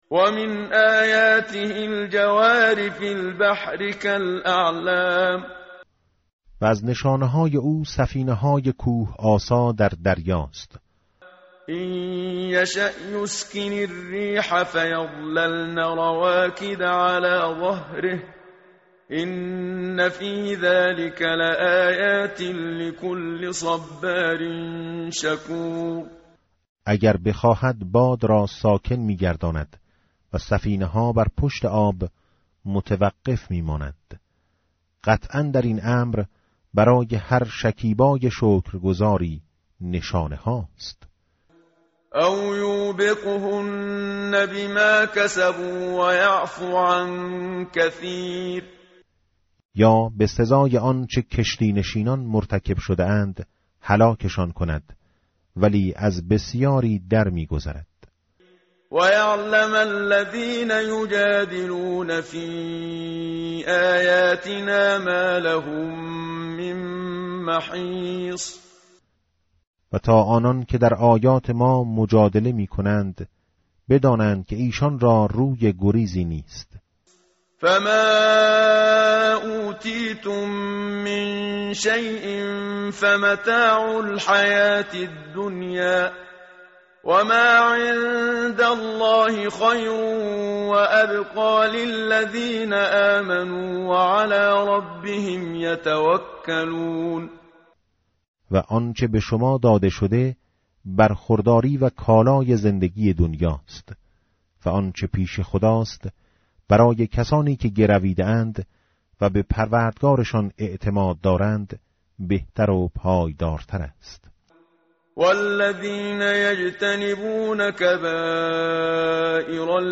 tartil_menshavi va tarjome_Page_487.mp3